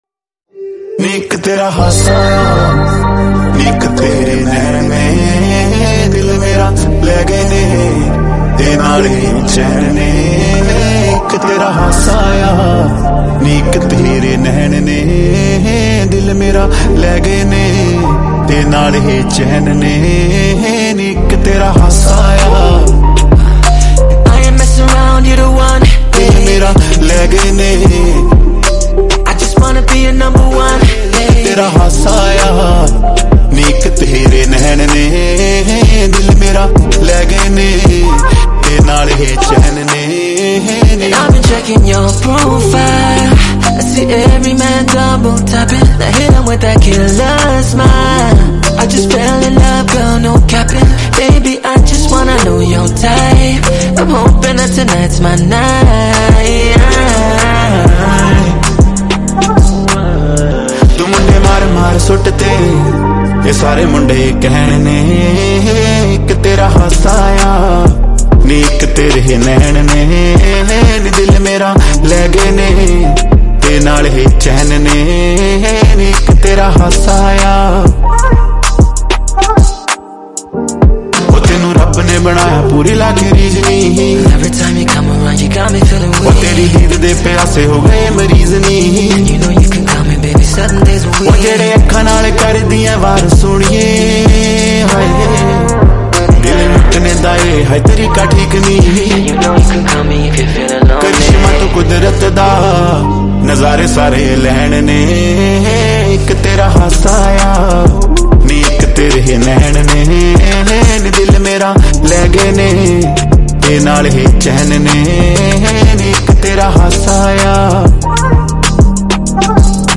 Song Genre : Punjabi Album